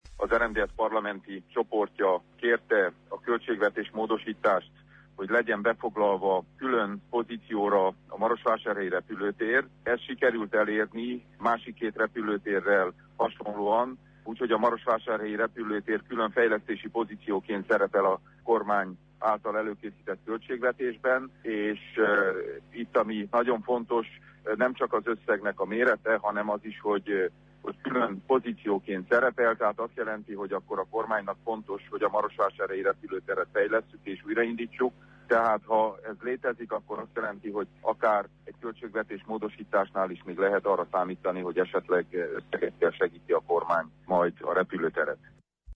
Péter Ferenc, a Maros Megyei Tanács elnöke rádiónknak elmondta, hogy abban az esetben, ha nem óvják meg a versenytárgyalást, év végéig elkészülhetnek a légikikötő felújításával, és újra indulhatnak a nagykapacitású gépek.